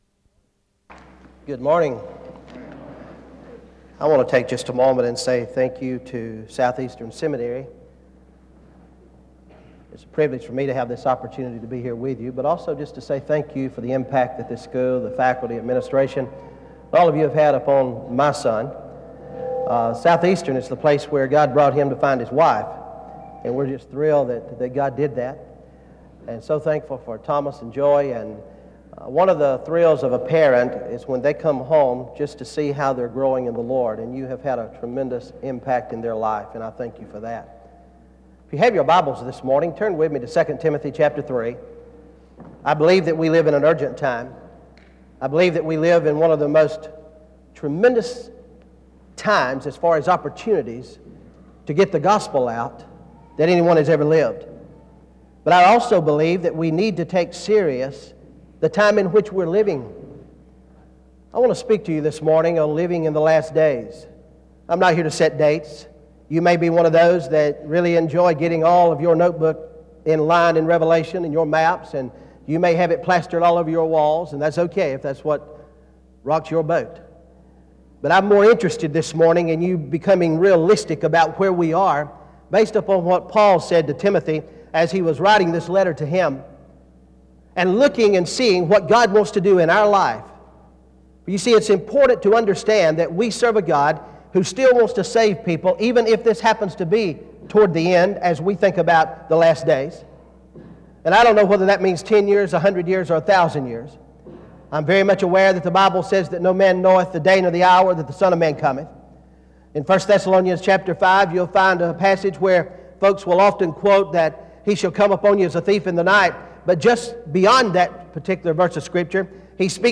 Wake Forest (N.C.)
SEBTS Chapel and Special Event Recordings - 2000s